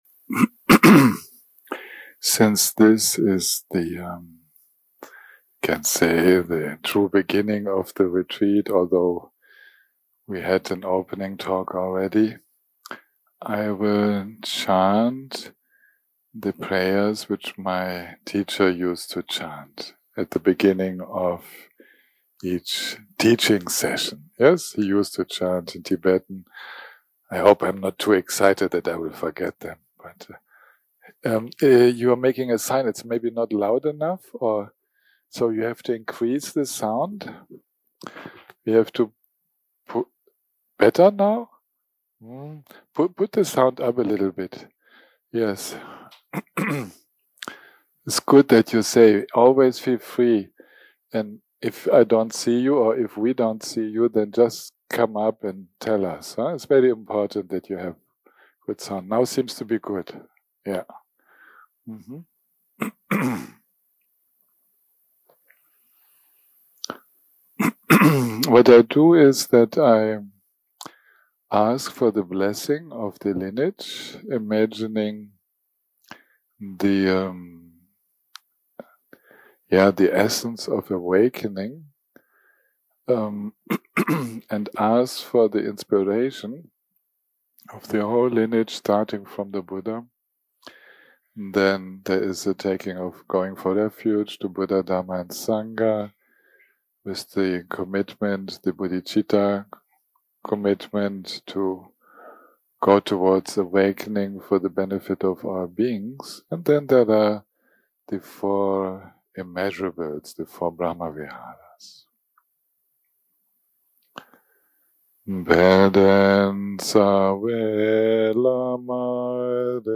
day 1 - recording 1 - Evening - Chanting + Introduction Dharma Talk